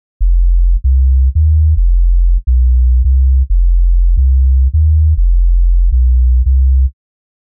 Хотите сказать, что не сможете услышать какой из этих басов в стерео?